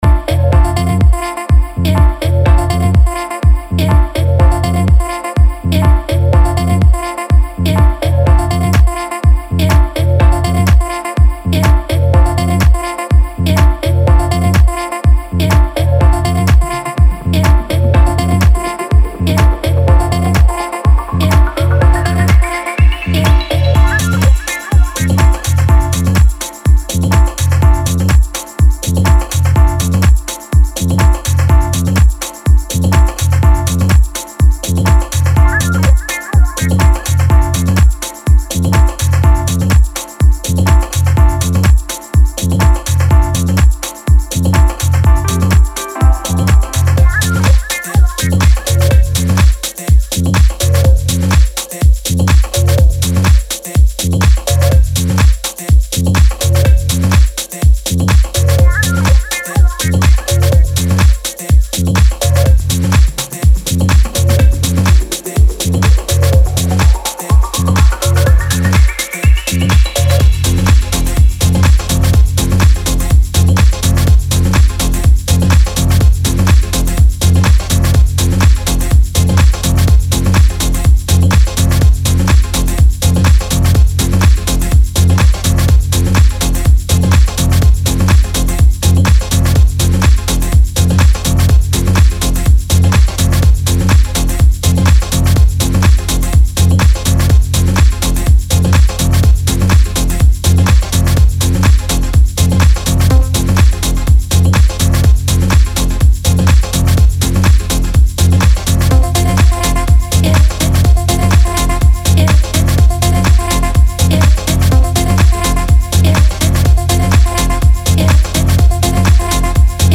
Style: Techno / Tech House